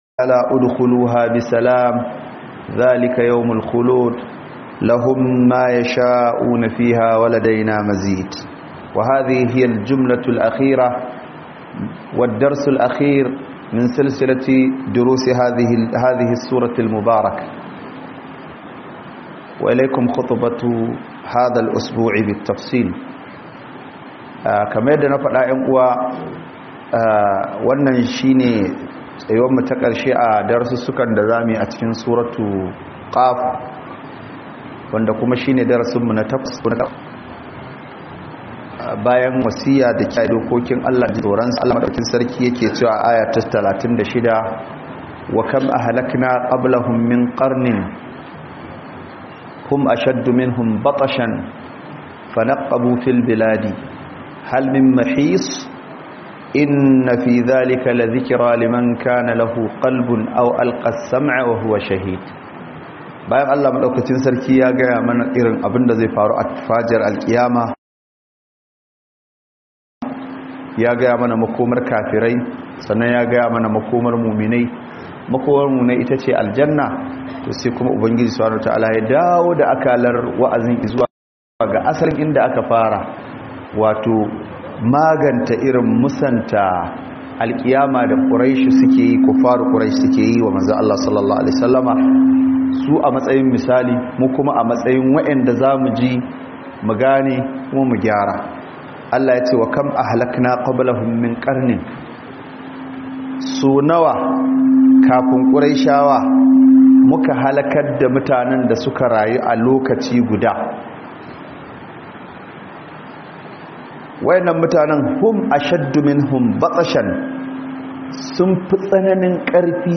Book Khuduba